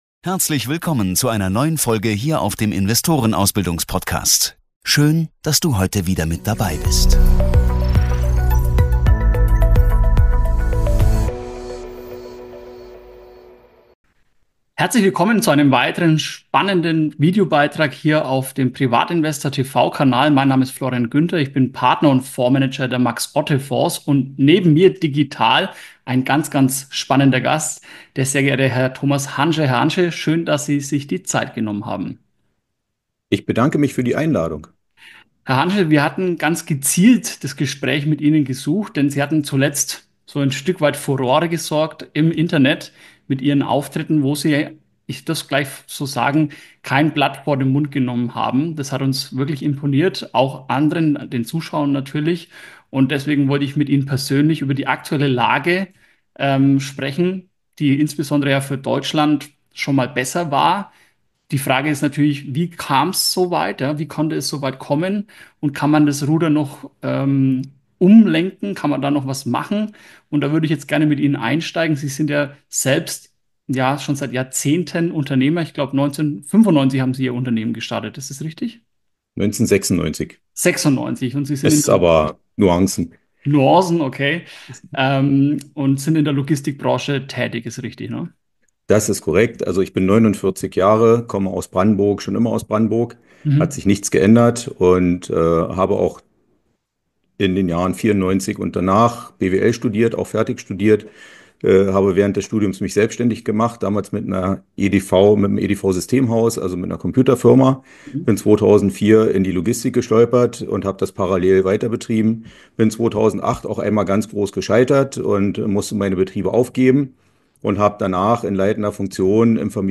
Ein spannendes Interview über die wahren Ursachen der aktuellen Wirtschaftskrise und mögliche Lösungswege.